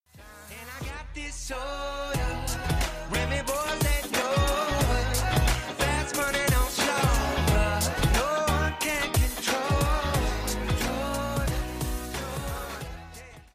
sub notification
sub-notification.mp3